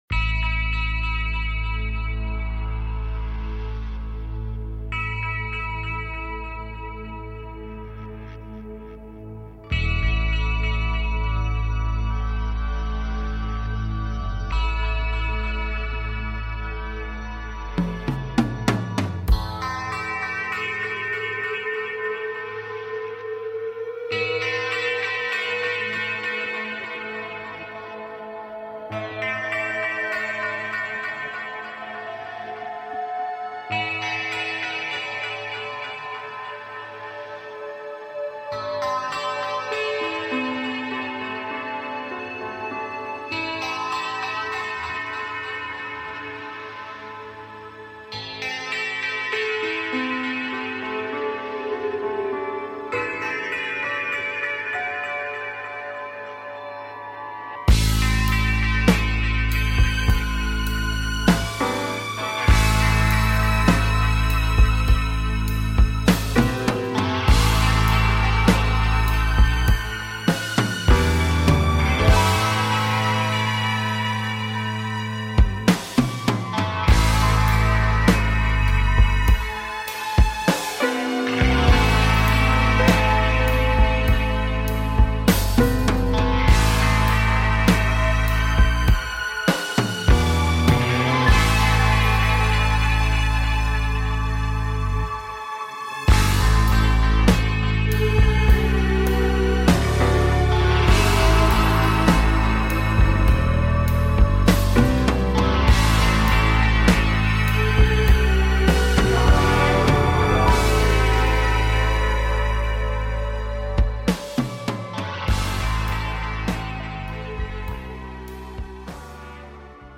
Reviewing listener projects and answering listener calls